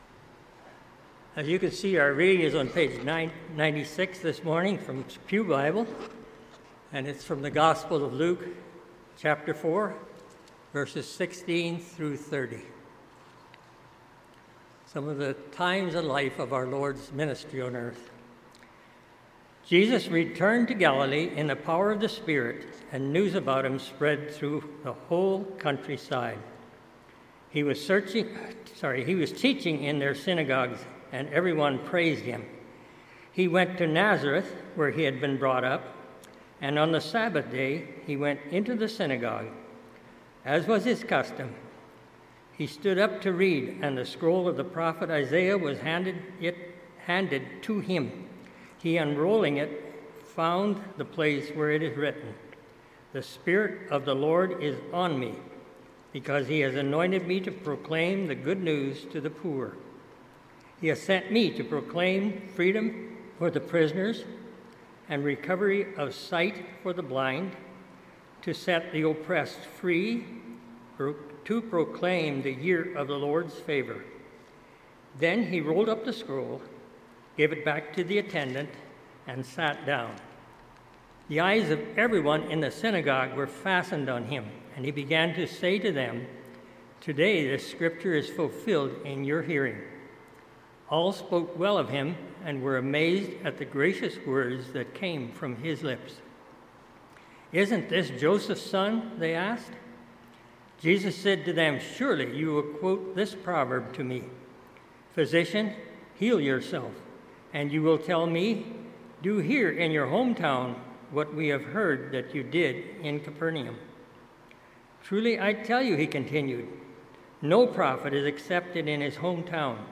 MP3 File Size: 33.6 MB Listen to Sermon: Download/Play Sermon MP3